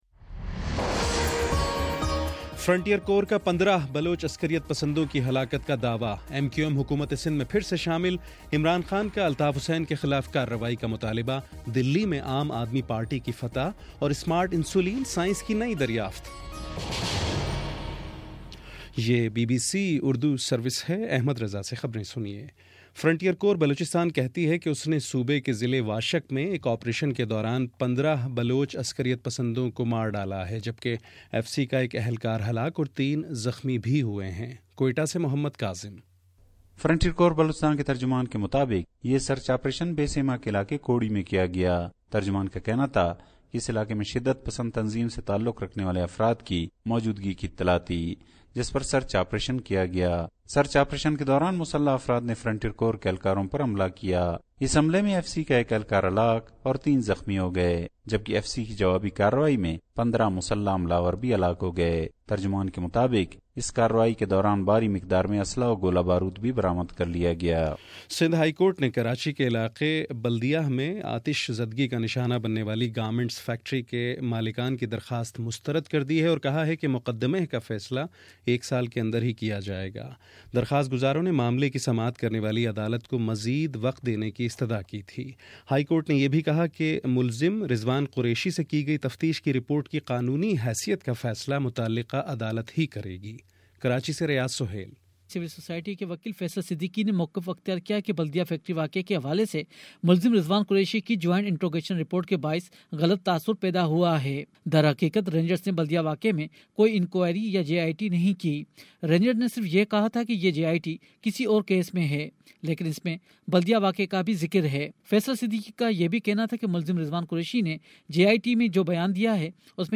فروری 10: شام چھ بجے کا نیوز بُلیٹن